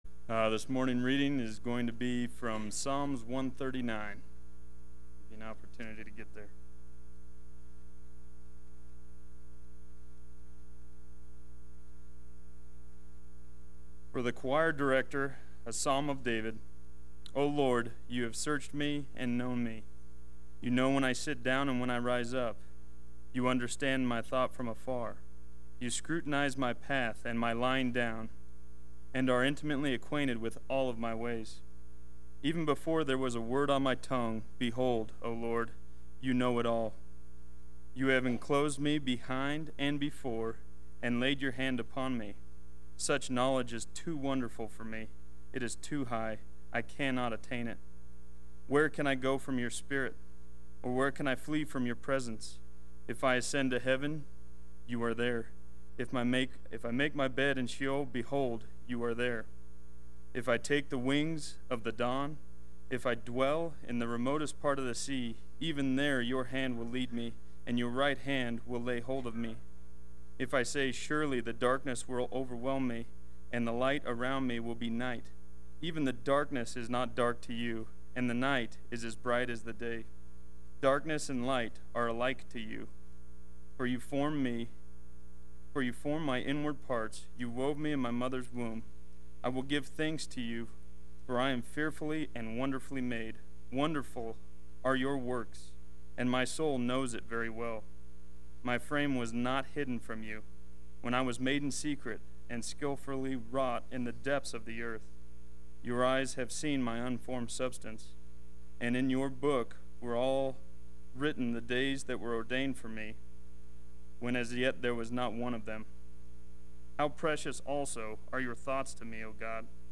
Play Sermon Get HCF Teaching Automatically.
Awesome God Sunday Worship